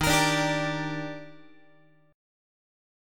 EbM7 chord